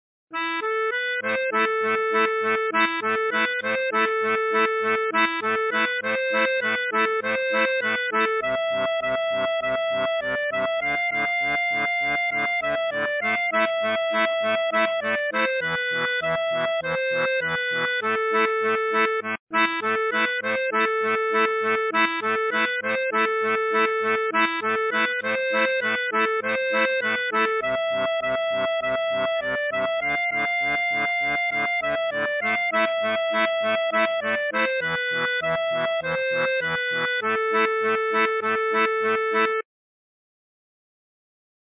Musique traditionnelle